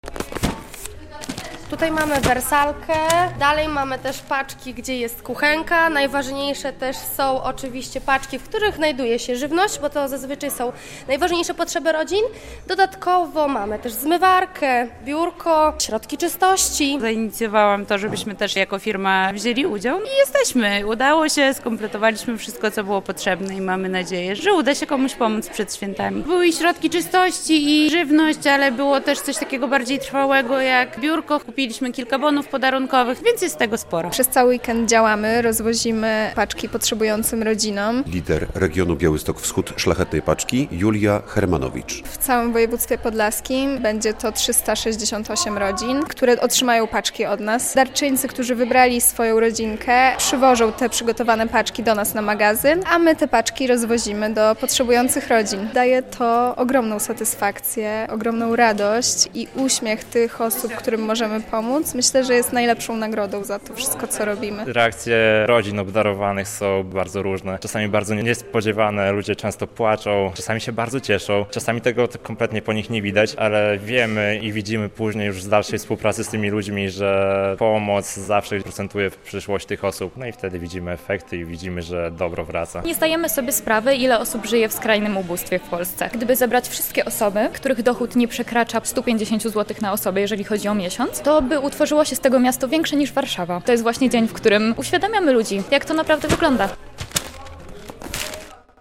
Radio Białystok | Wiadomości | Wiadomości - Weekend cudów, czyli finał akcji "Szlachetna Paczka"